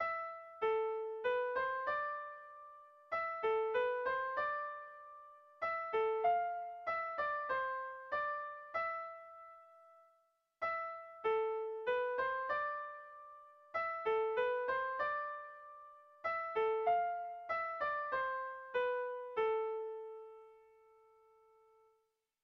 Sentimenduzkoa
Lauko handia (hg) / Bi puntuko handia (ip)